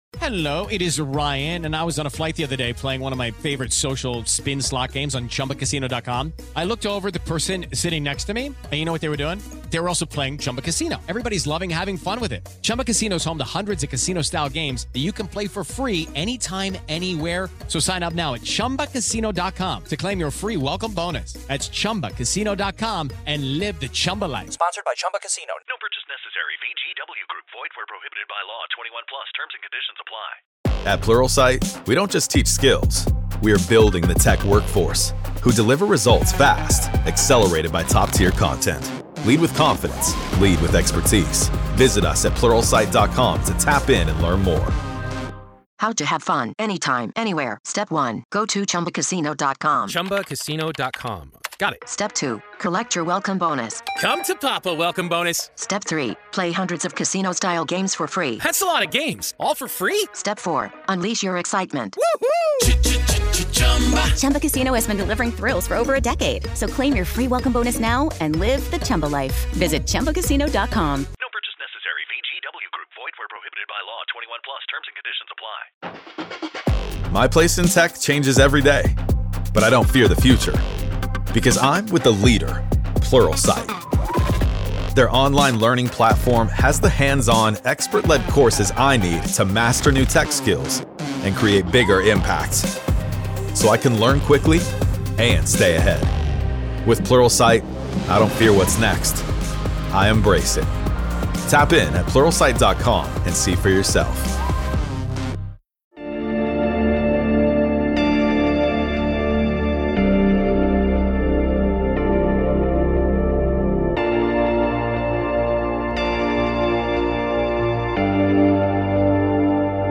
An exclusive interview with Congressman Eric Burlison as he joins me to discuss the Age of Disclosure documentary, the involvement of notable figures like Marco Rubio and James Clapper, and the efforts to pass the UAP Disclosure Act. Burlison discusses the challenges and progress in gaining congressional approval and his hopes for mainstream attention to the documentary. The interview also touches on Burlison's personal journey, his visit to Peru to study alleged mummies, and his hopes for presidential action on UAP disclosure.